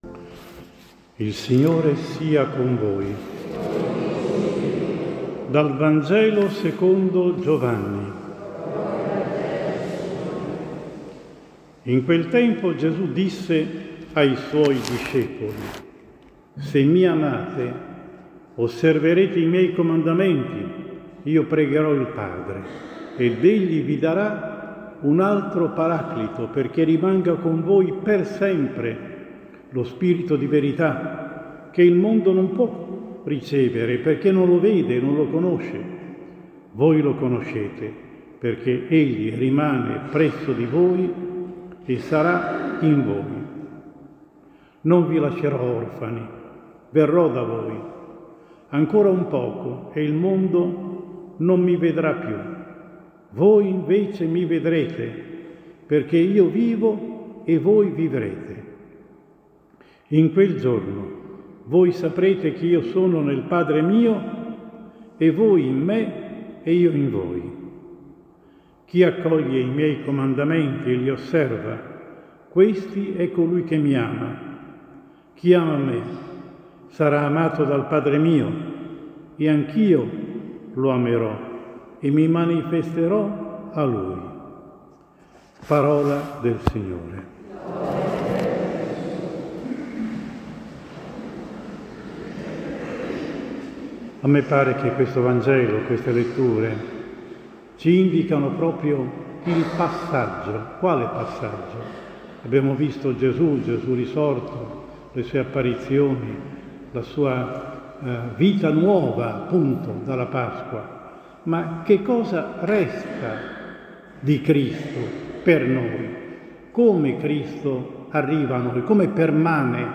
14 Maggio 2023, VI DOMENICA DI PASQUA, anno A: omelia